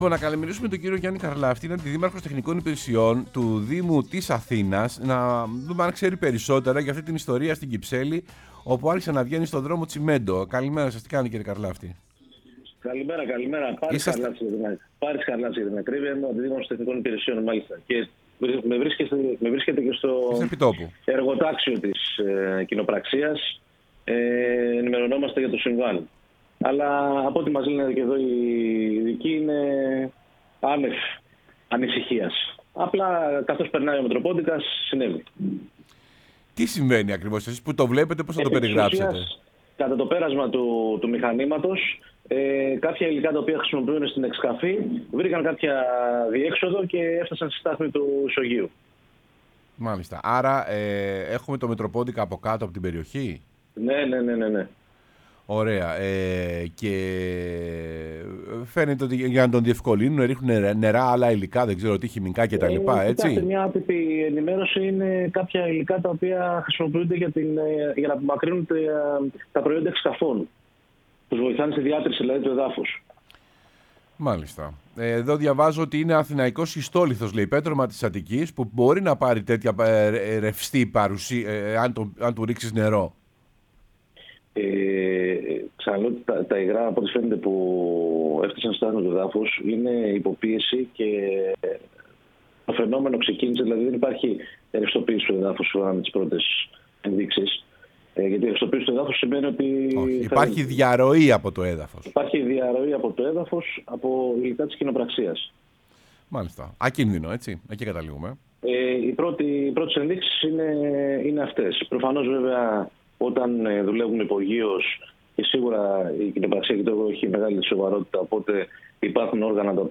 Ο Πάρης Χαρλαύτης, Αντιδήμαρχος Τεχνικών Υπηρεσιών του Δήμου Αθηναίων , μίλησε στην εκπομπή «Σεμνά και Ταπεινά»